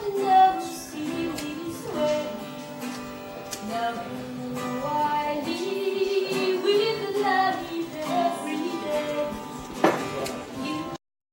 La source sonore n'est pas visible dans le champ mais fait partie de l'action.